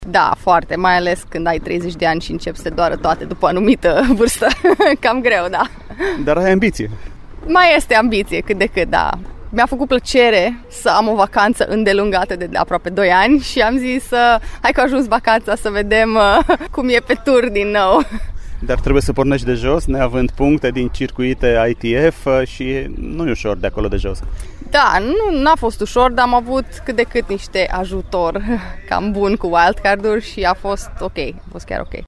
Aflată într-o scurtă vacanţă la Timişoara, Edina Gallovits a oferit un interviu postului nostru de radio, pe care îl puteţi asculta în forma integrală sâmbătă, la „Arena Radio”, emisiune ce începe după ştirile orei 11,00.